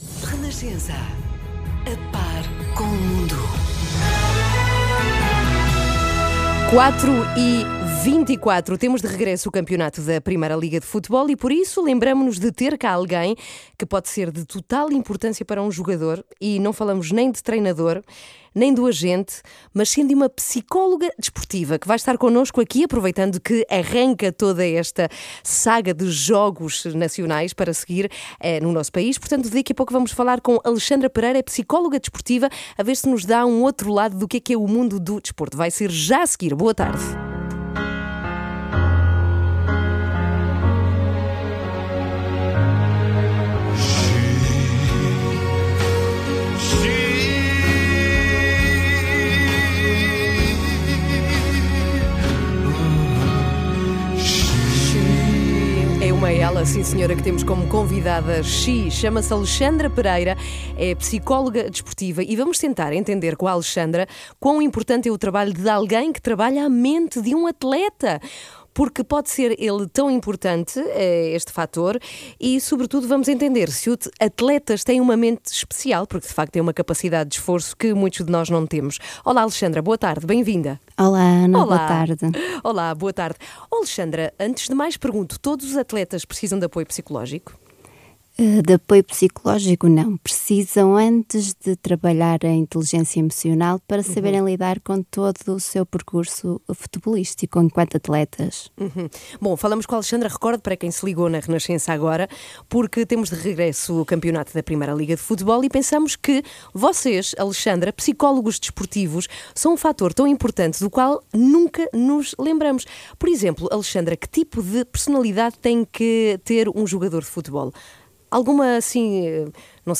esteve na Rádio Renascença a falar um pouco sobre o futebol e as vantagens do apoio psicológico na prática de desporto de alta competição.